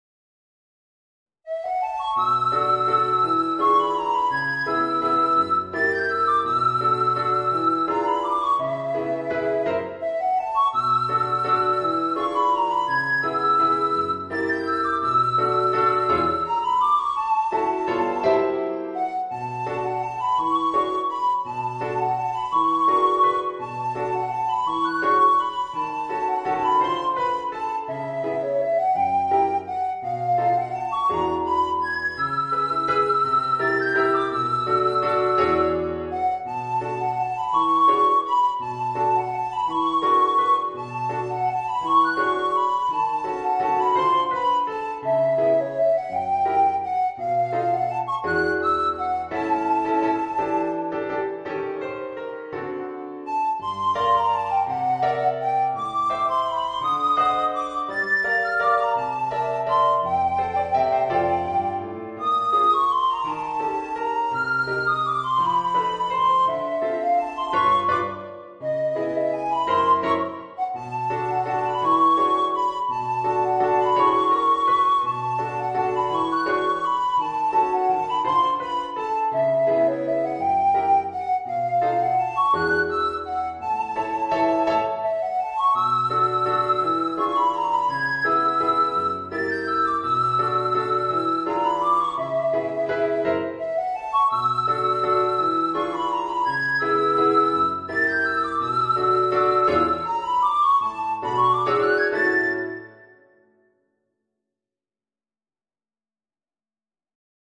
Soprano Recorder & Piano